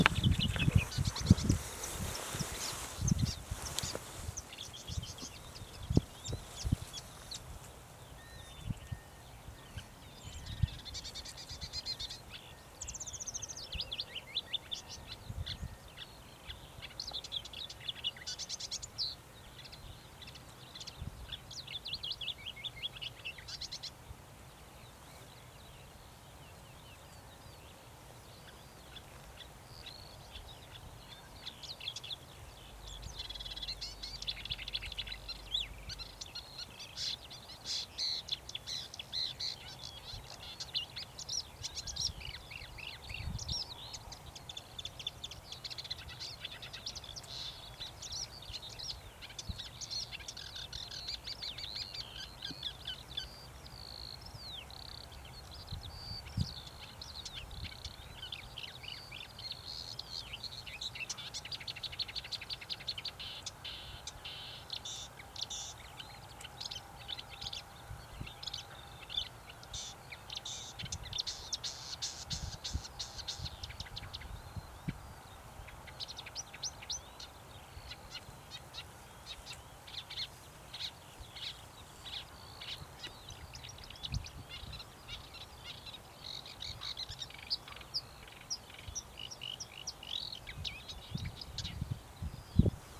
болотная камышевка, Acrocephalus palustris
СтатусПоёт